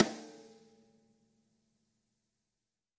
描述：14x7黄铜军鼓。这个圈套很好，所以我没有做太多的处理。切几个频率并使用HComp压缩器。
标签： 混响 金属 击鼓 14 打孔 低音 潮湿 低温 鼓手 昏昏欲睡 死亡 节拍 SAMPL E 功能强大
声道立体声